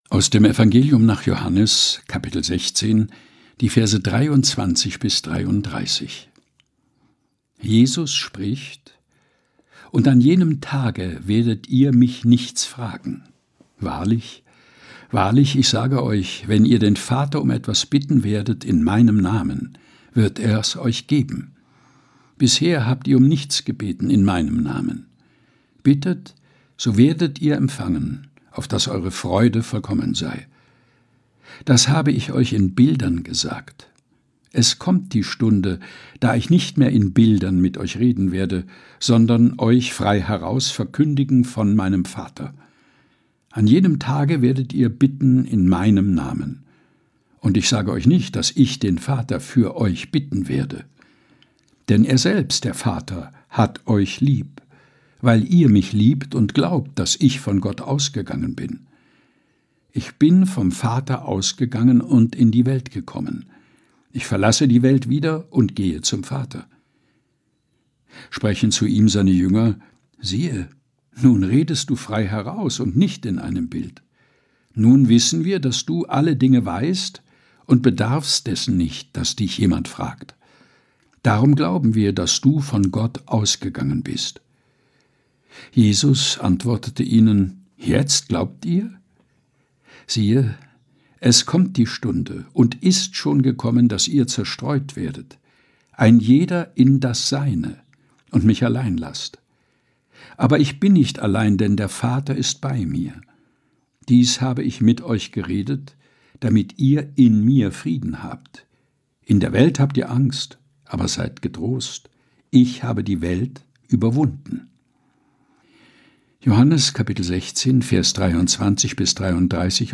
Predigttext zum Sonntag 2025.